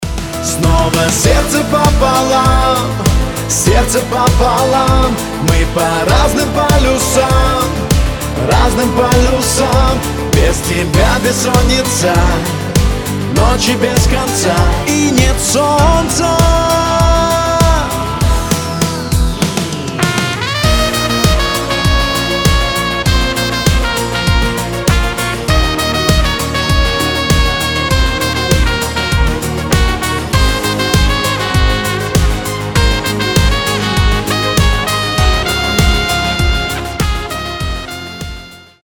поп
Саксофон
романтичные